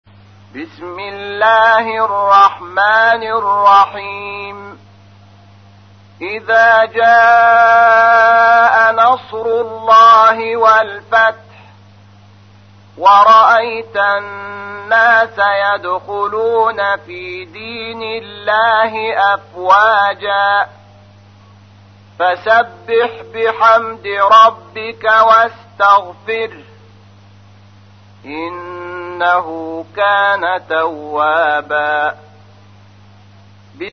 تحميل : 110. سورة النصر / القارئ شحات محمد انور / القرآن الكريم / موقع يا حسين